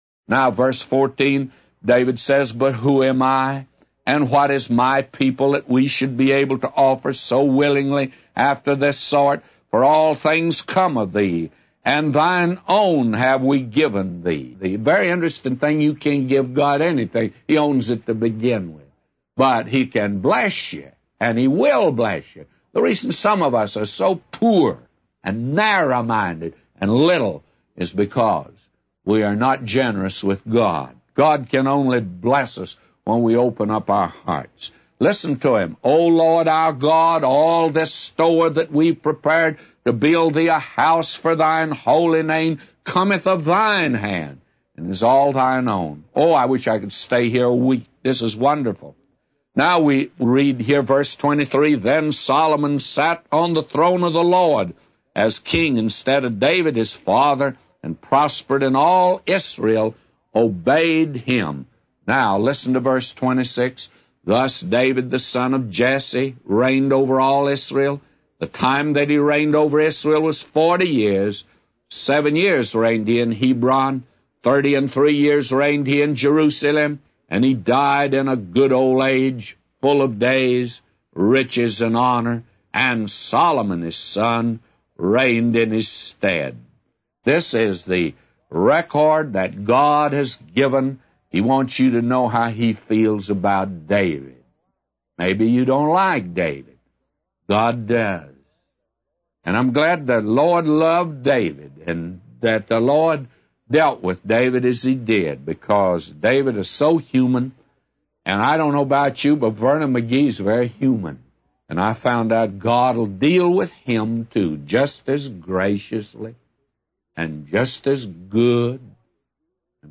A Commentary By J Vernon MCgee For 1 Chronicles 29:15-999